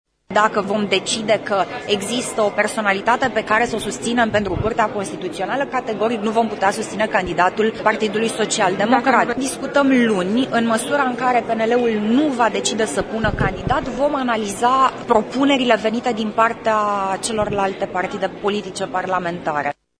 Copreşedintele PNL, Alina Gorghiu, a afirmat că, dacă liberalii nu vor avea nicio propunere, lE vor analiza pe cele venite din partea celorlalte partide: